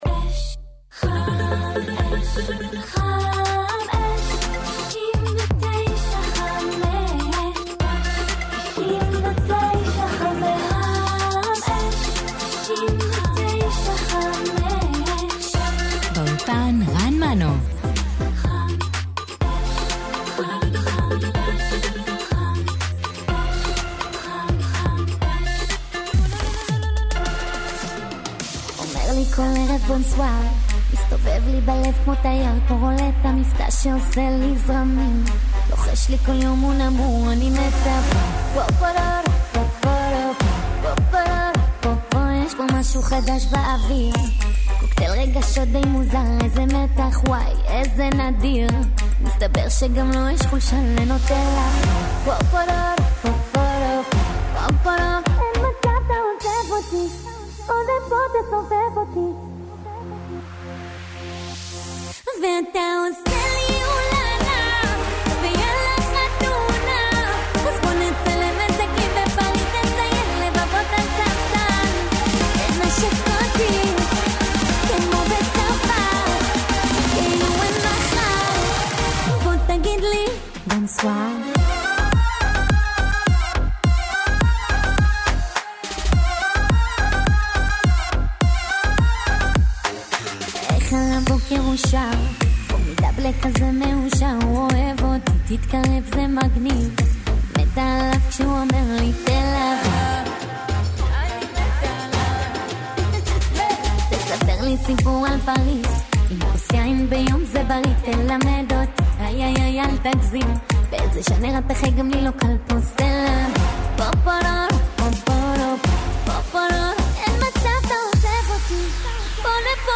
בלהיטים מזרחיים עכשוויים ונוסטלגיים ברצף